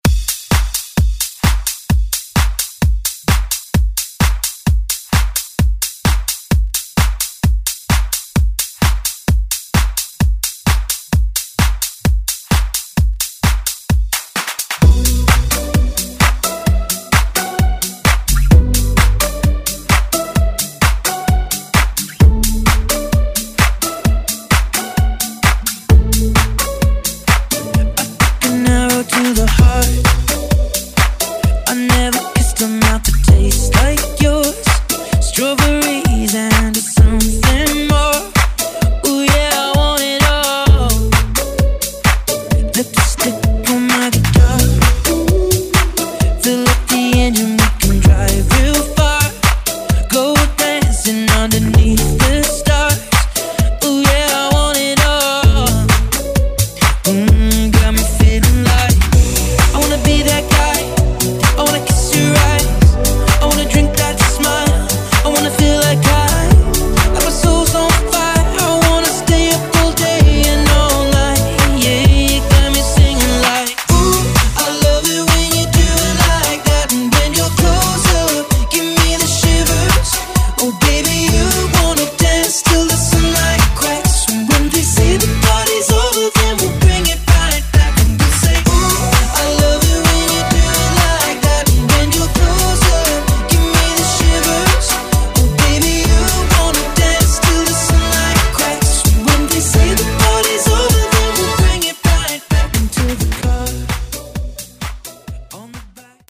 Genres: DANCE , RE-DRUM , TOP40 Version: Dirty BPM: 130 Time